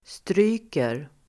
Uttal: [str'y:ker]